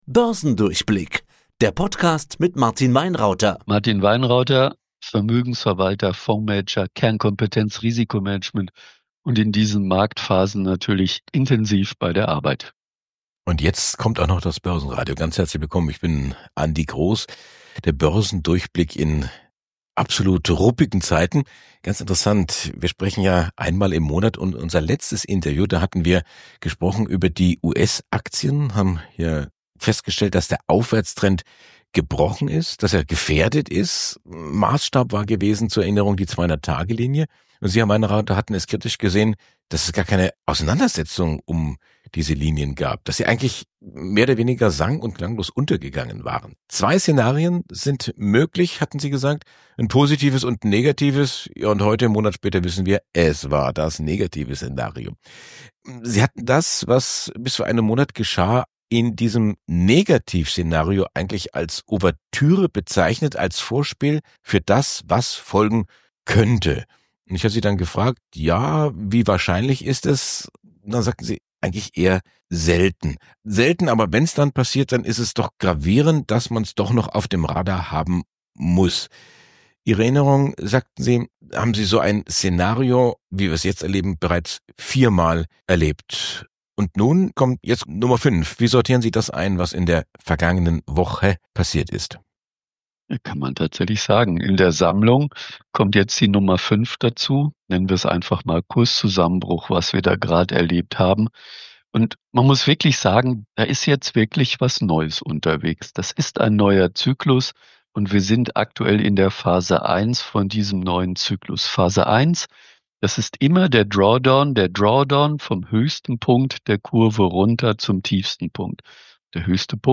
Ein Gespräch über kluge Strategien in unsicheren Zeiten – und die Kunst, Panik zu vermeiden.